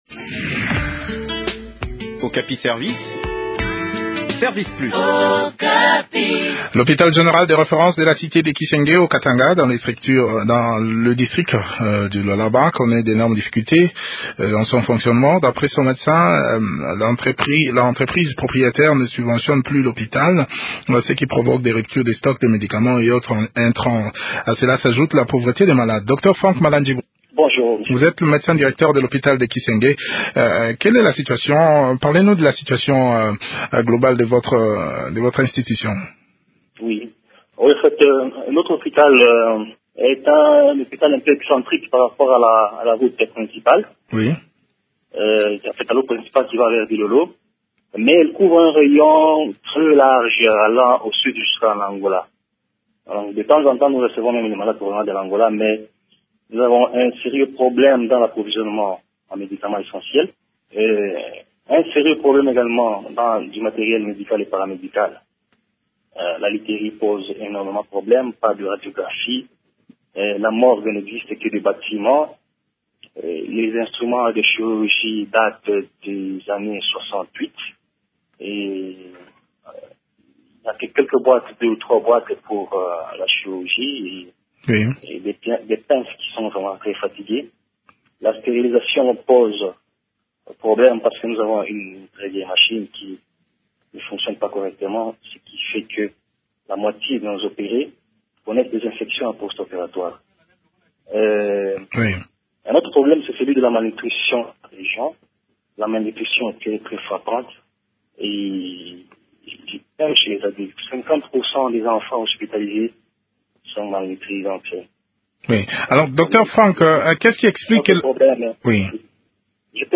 Le point de la situation sur le terrain dans cet entretien